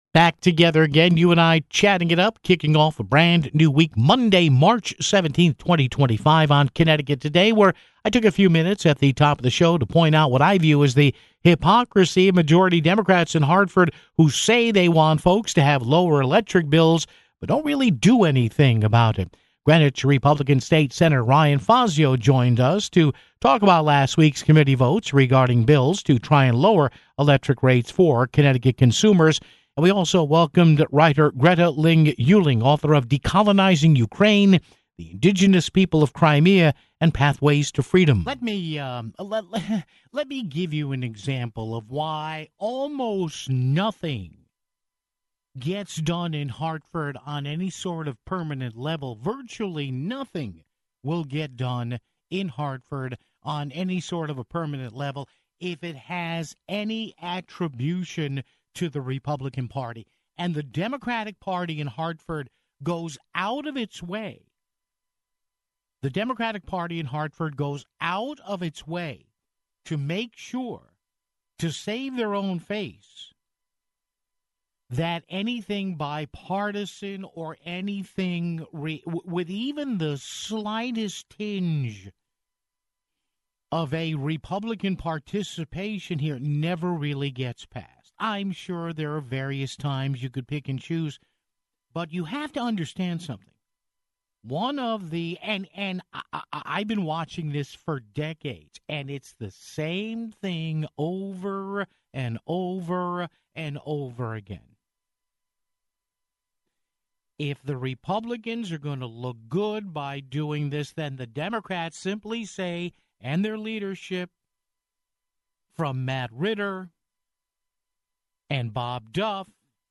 Greenwich GOP State Sen. Ryan Fazio joined us to discuss last week's committee votes regarding bills to try and lower electricity rates for Connecticut consumers (15:26).